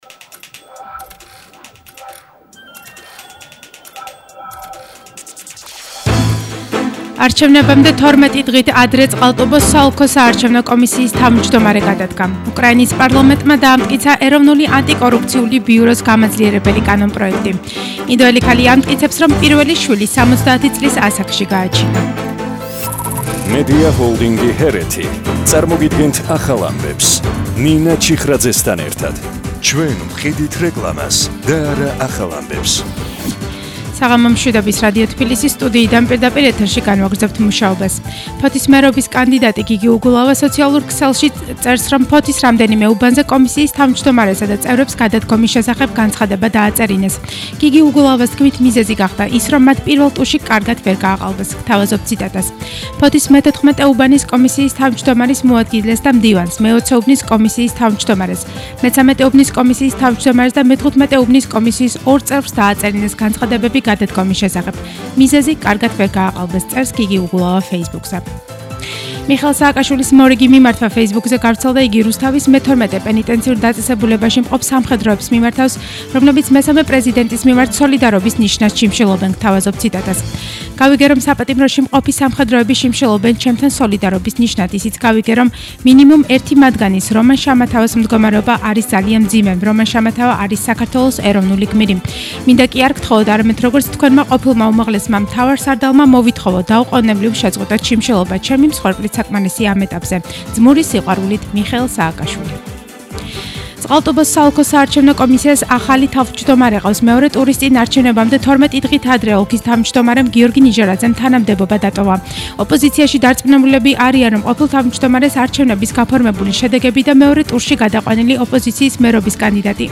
ახალი ამბები 20:00 საათზე –19/10/21